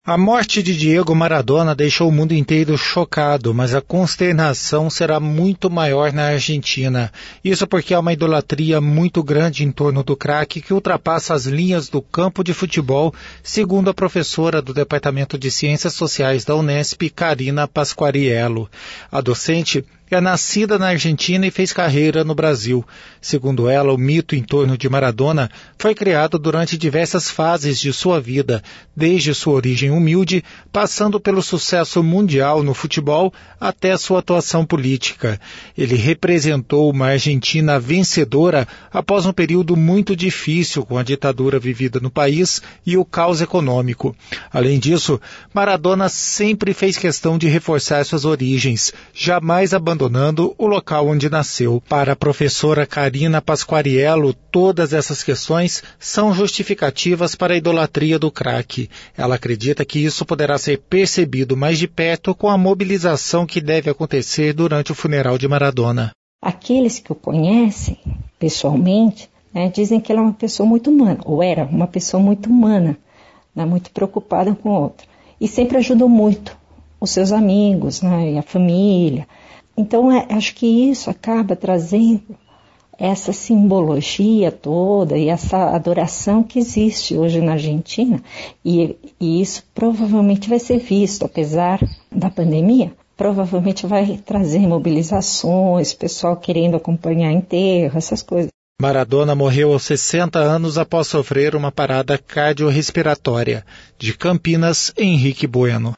Idolatria a Maradona vai além do futebol, diz cientista social